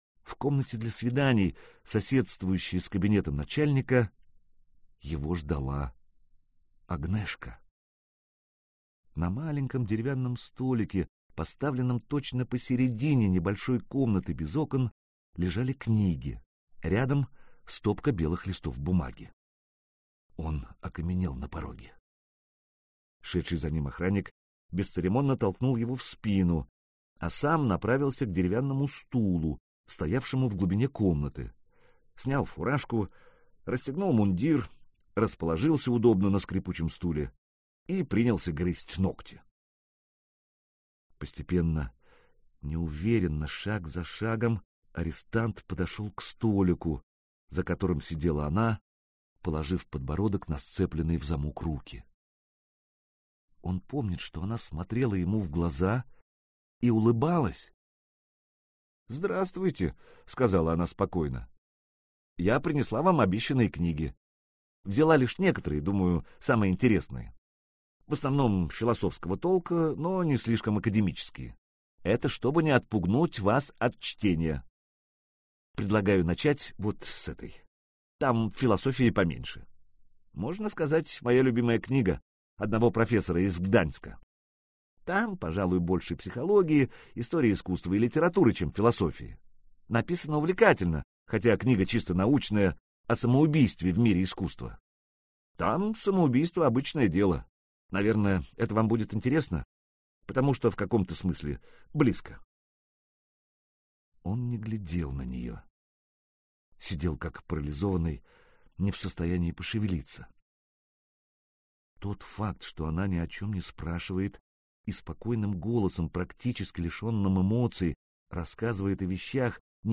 Аудиокнига Прости… - купить, скачать и слушать онлайн | КнигоПоиск